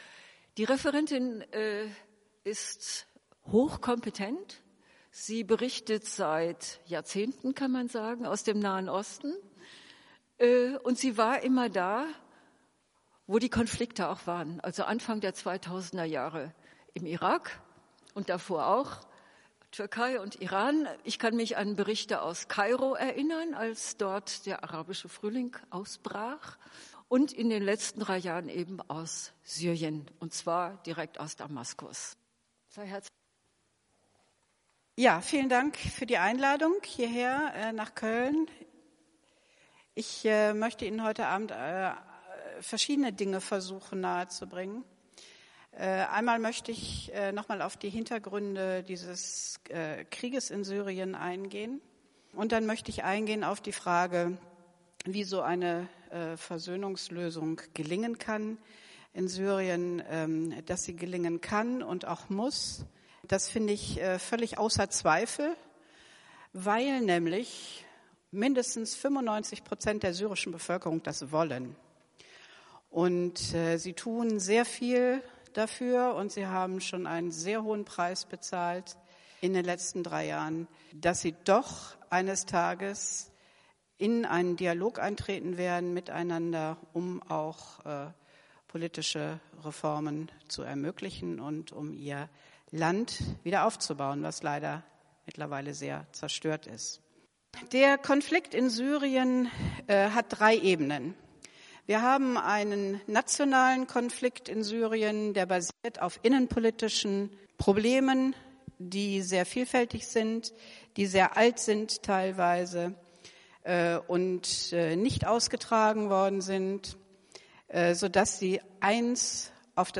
informiert in dem nachfolgenden Vortrag in der Kölner Martin-Luther-Kirche darüber und macht Vorschläge